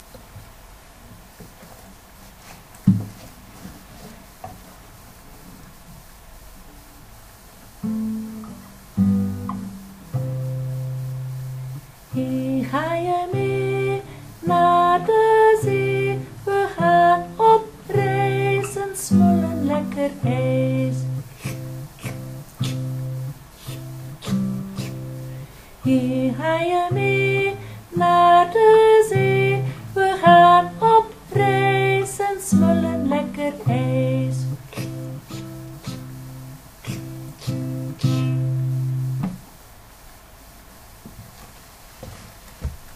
lied
hieronder mp3 lied dat jullie peuter kan zingen